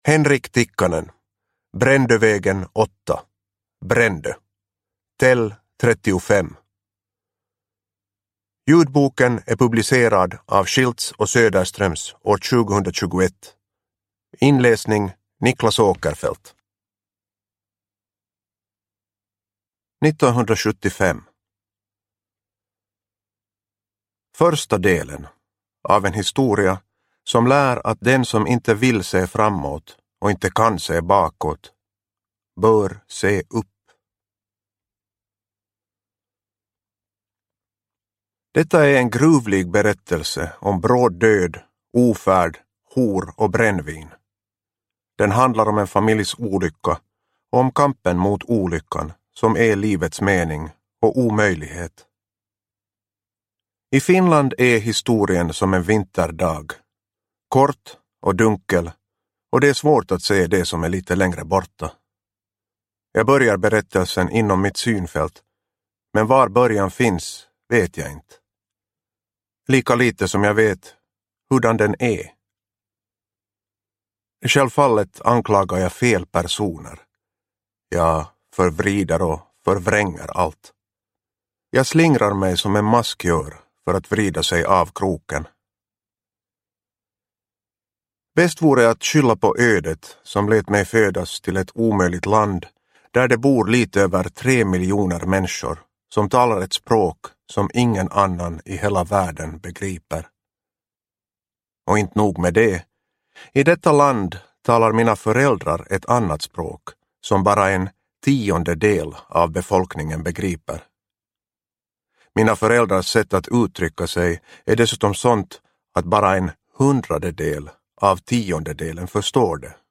Brändövägen 8 Brändö tel. 35 – Ljudbok – Laddas ner